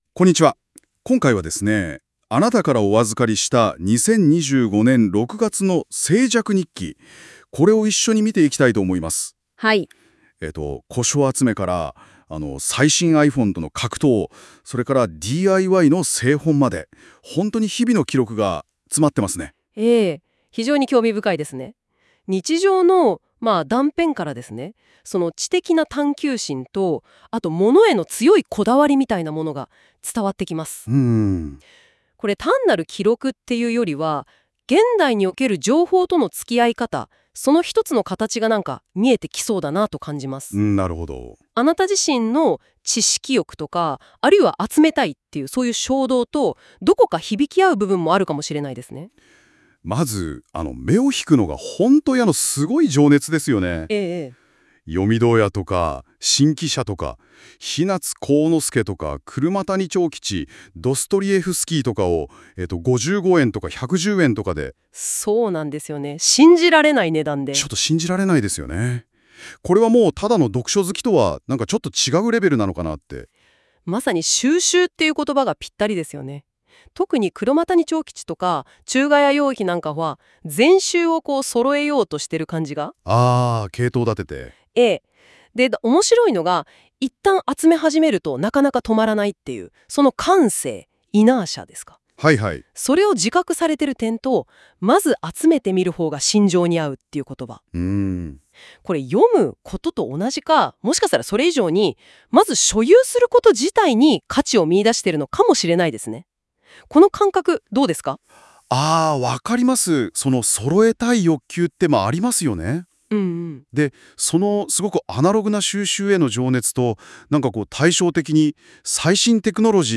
数秒?数分で、2人のAIホストによる対話形式の音声が自動生成されます。
数分で、ラジオのDJ風の会話が生成された。
ラジオのDJ風の会話 wavデータ　16MBくらい